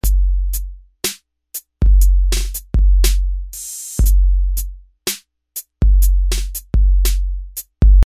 描述：808鼓循环
Tag: 120 bpm Hip Hop Loops Drum Loops 1.34 MB wav Key : Unknown